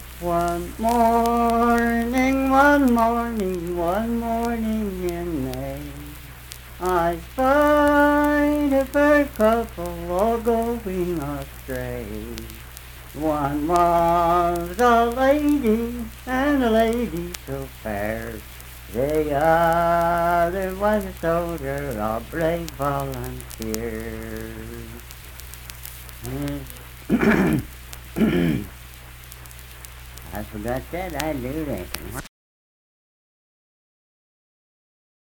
Unaccompanied vocal music performance
Verse-refrain 1(4).
Voice (sung)